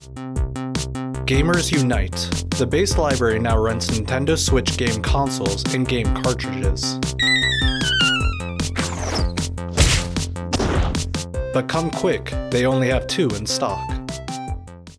This spot was created for Incirlik Armed Forces Network radio show.